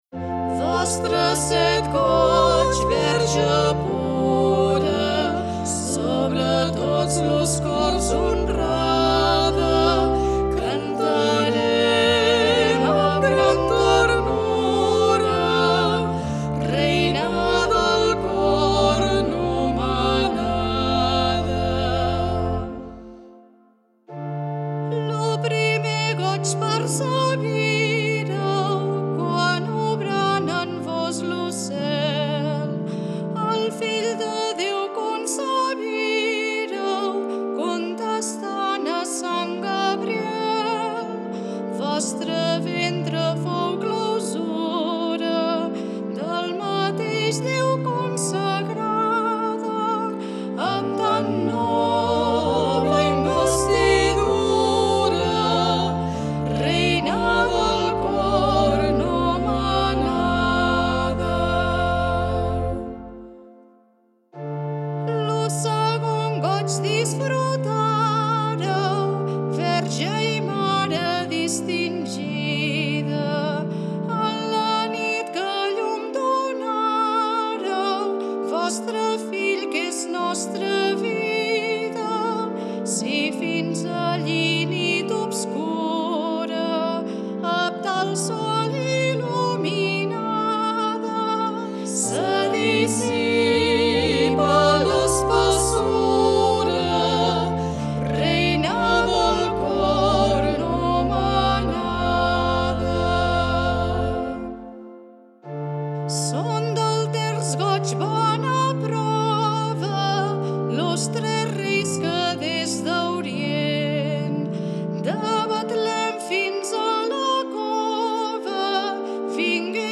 A continuació trobareu diverses músiques populars religioses de Montblanc digitalitzades amb motiu de l’Any de la Mare de Déu (setembre 2021- setembre 2022):